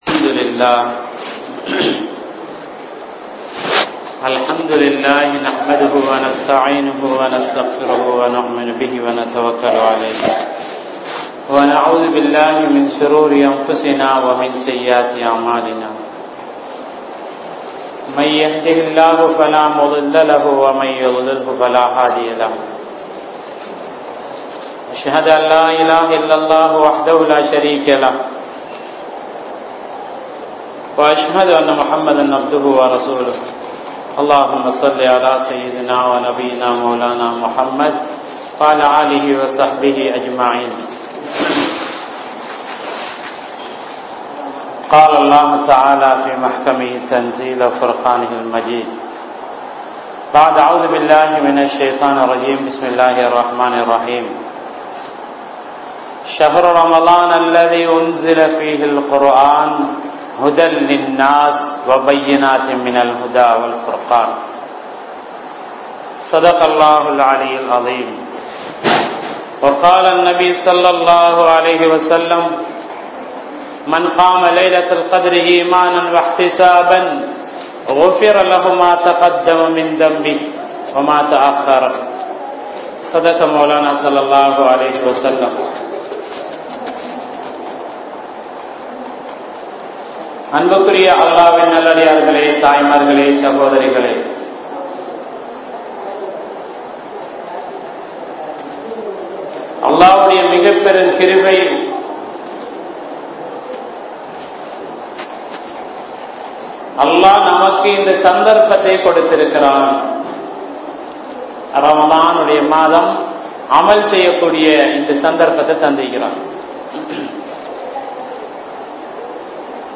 Islamiya Pengal | Audio Bayans | All Ceylon Muslim Youth Community | Addalaichenai